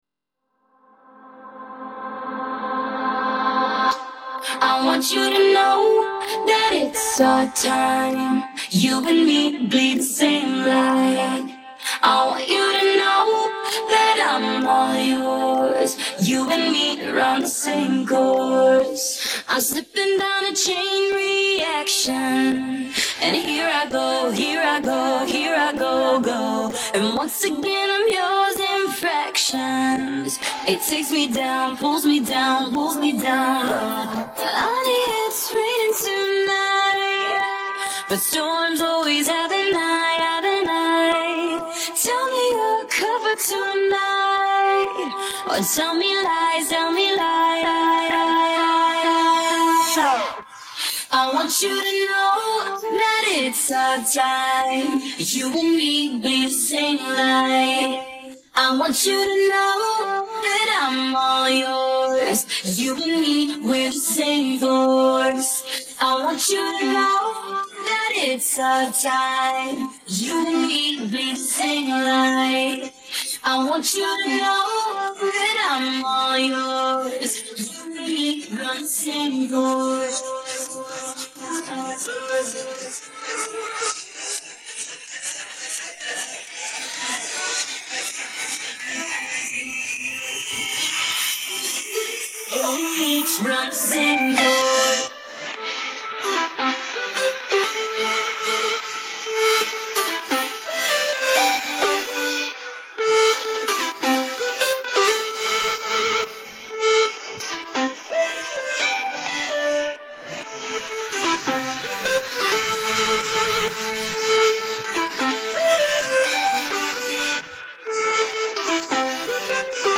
ส่วนเสียง